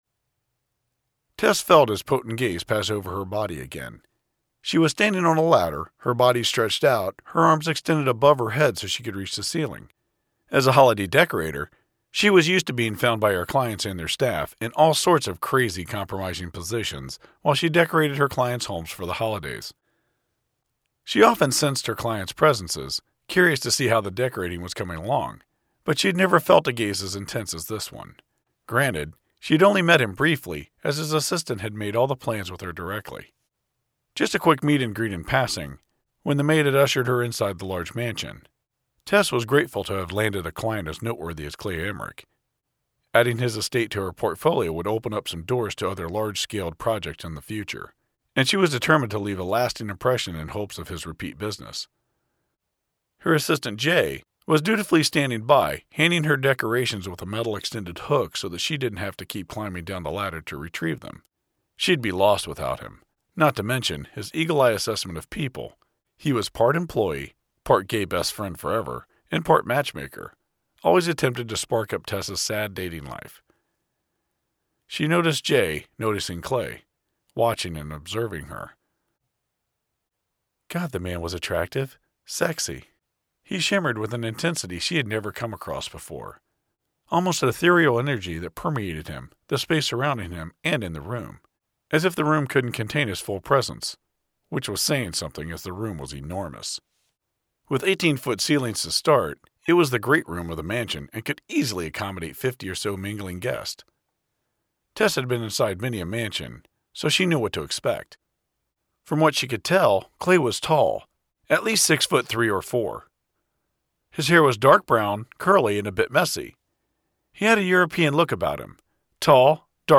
Here is a sampling of my audiobook narration projects and convenient links to them.